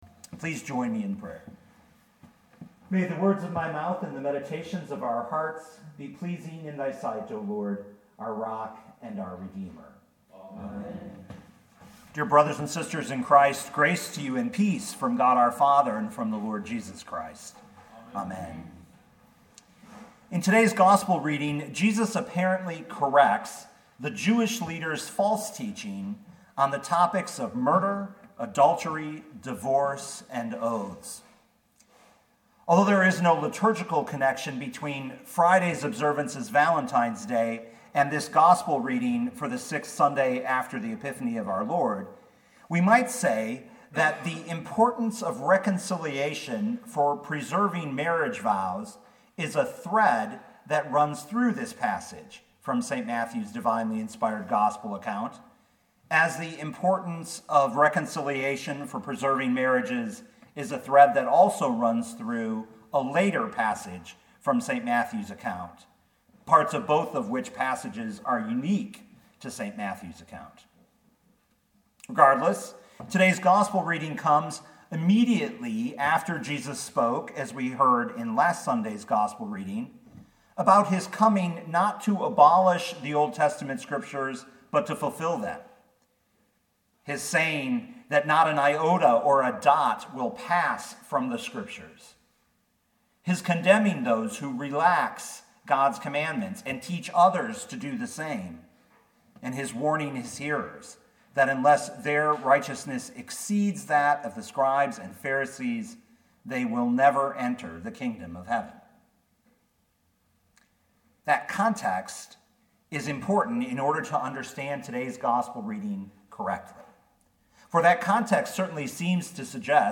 2020 Matthew 5:21-37 Listen to the sermon with the player below, or, download the audio.